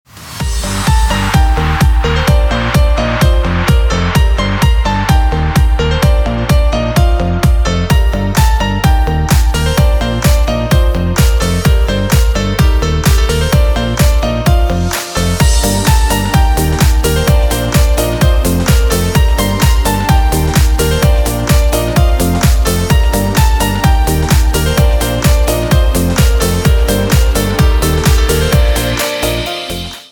мелодичный рингтон 2026